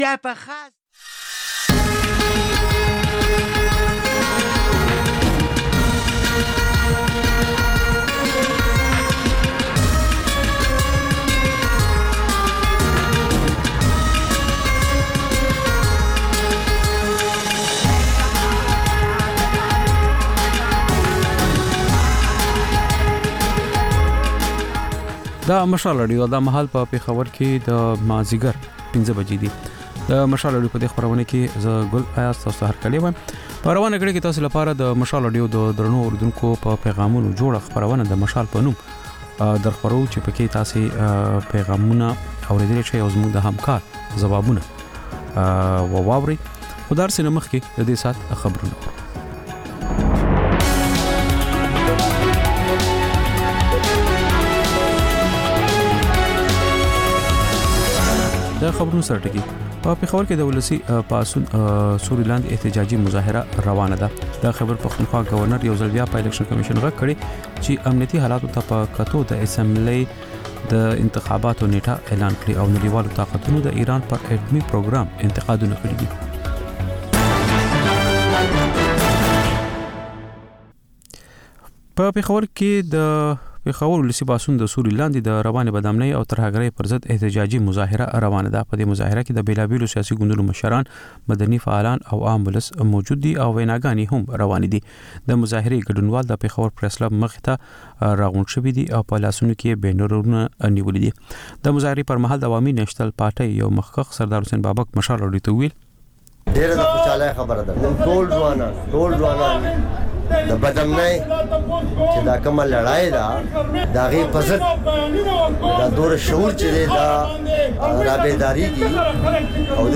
د مشال راډیو ماښامنۍ خپرونه. د خپرونې پیل له خبرونو کېږي، بیا ورپسې رپورټونه خپرېږي.
ځېنې ورځې دا مازیګرنۍ خپرونه مو یوې ژوندۍ اوونیزې خپرونې ته ځانګړې کړې وي چې تر خبرونو سمدستي وروسته خپرېږي.